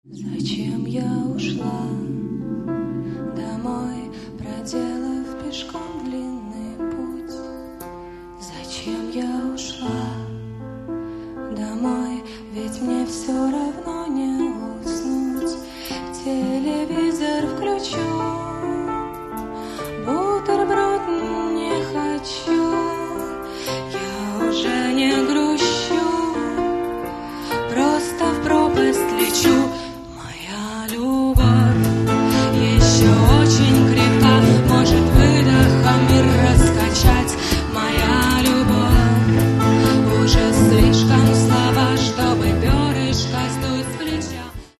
Каталог -> Рок и альтернатива -> Лирический андеграунд
(live)